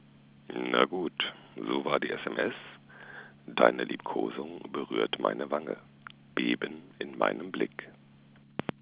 ooooh Mann, du hast ja echt ne super schöne Stimme.... Mehr!